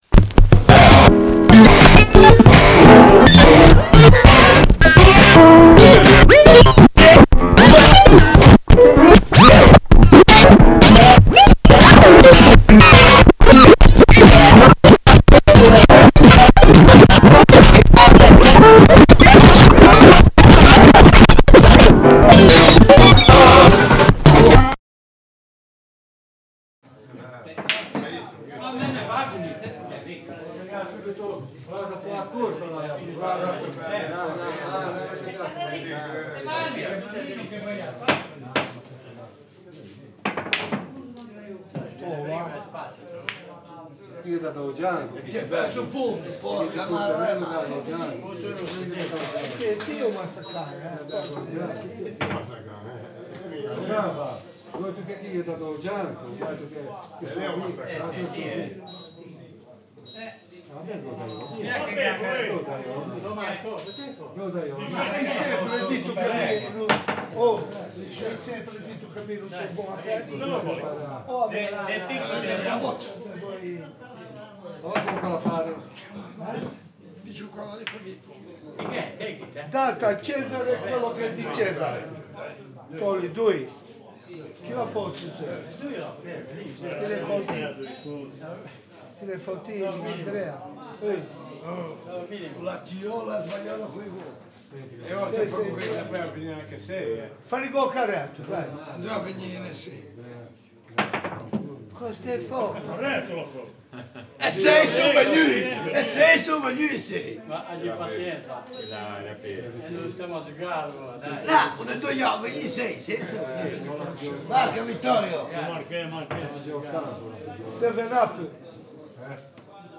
Forme participative de création sonore
Le but est de créer un cadavre exquis sonore dans lequel la thématique proposée est abordée sous plusieurs points de vue et à plusieurs degrés.
La récolte de sons est en quelque sorte une forme de création sonore populaire parce qu’elle mélange à la fois des enregistrements sonores réalisés par des professionnels, et des amatrices et amateurs du son.